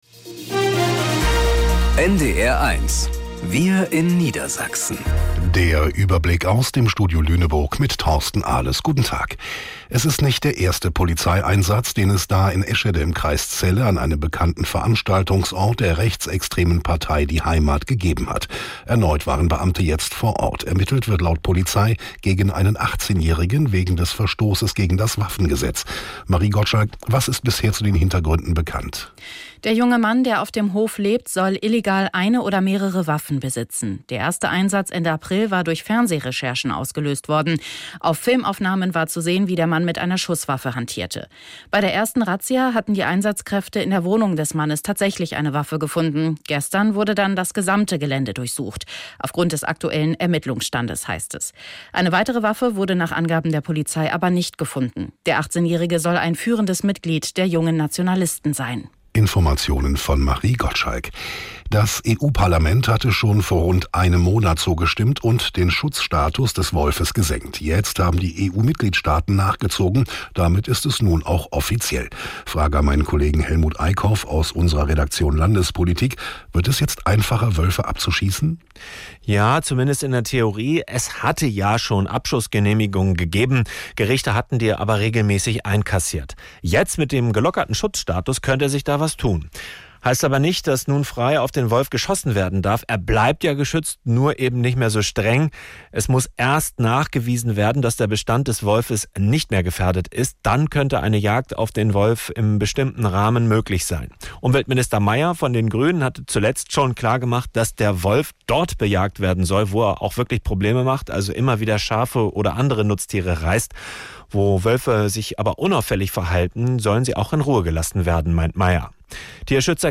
Wir in Niedersachsen - aus dem Studio Lüneburg | Nachrichten